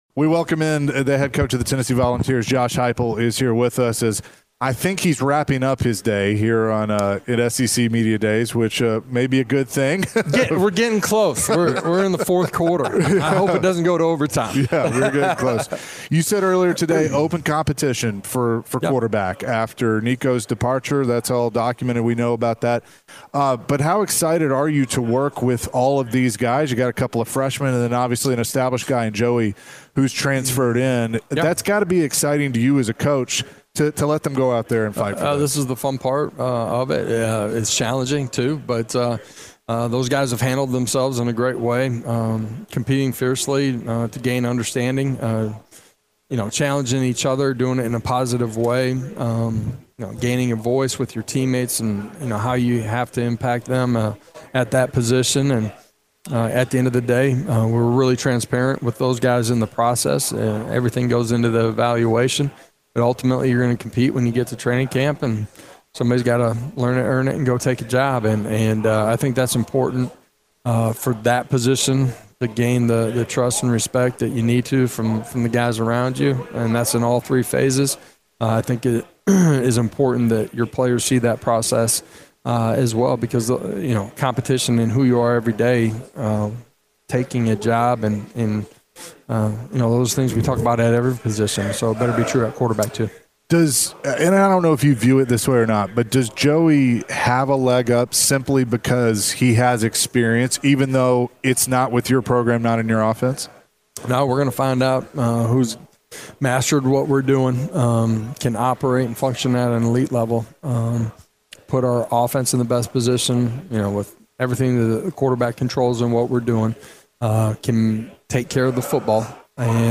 The guys chatted with Tennessee Volunteers Head Coach Josh Heupel. Coach Heupel spoke on the QB challenge.